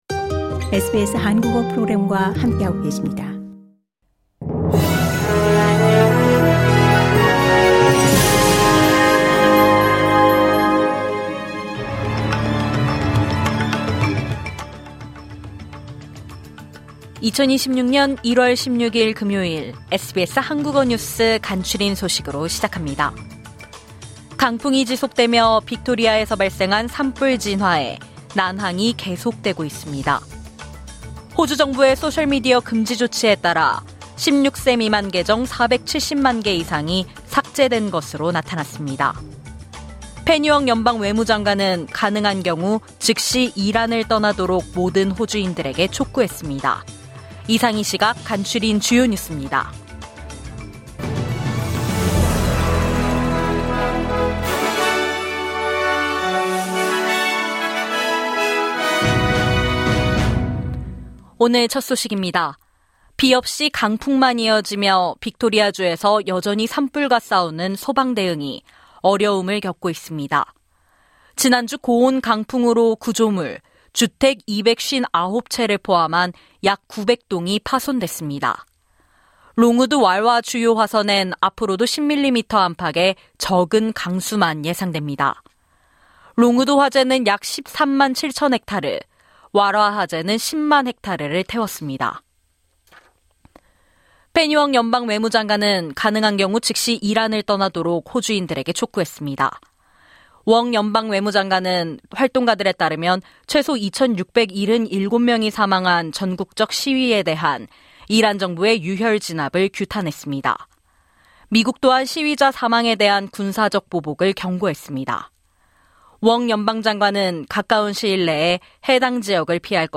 하루 10분 호주 뉴스: 1월 16일 금요일